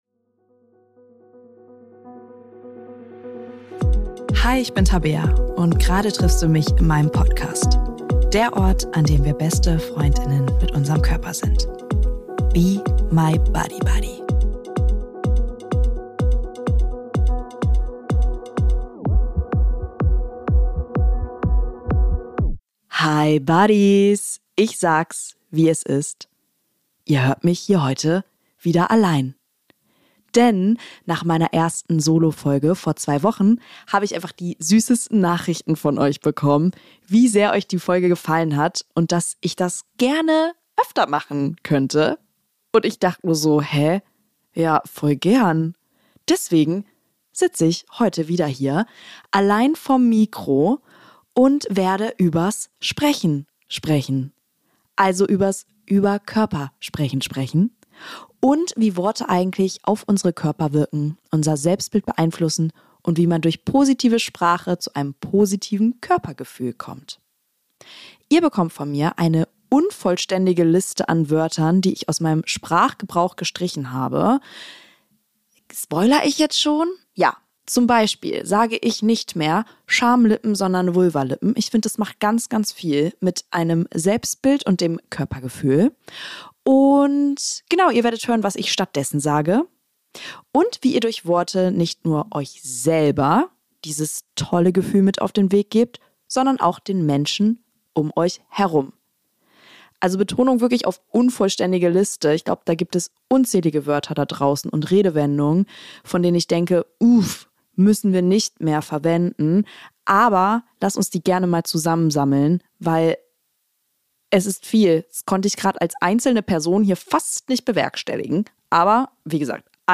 In dieser Solo-Folge spreche ich darüber, wie Sprache unser Körperbild und unser Selbstwertgefühl beeinflusst – und warum es so wichtig ist, achtsam mit Worten über Körper umzugehen.